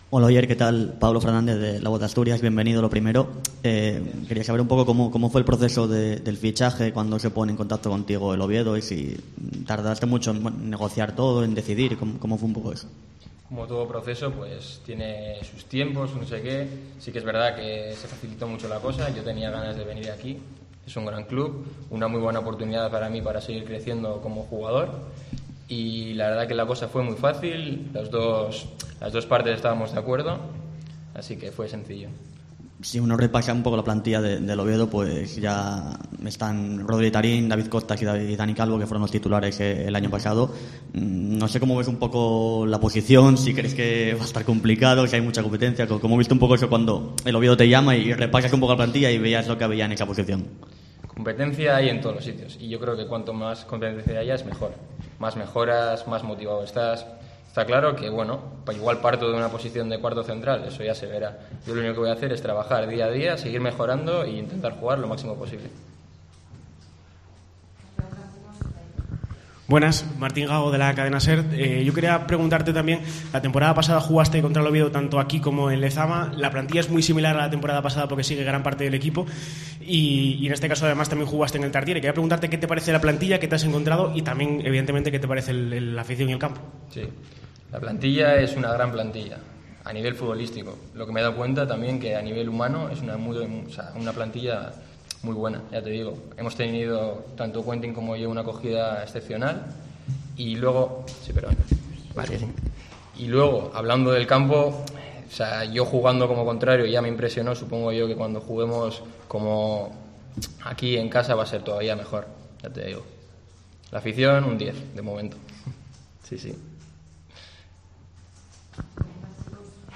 PRESENTACIÓN OFICIAL